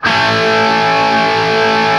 TRIAD A  L-L.wav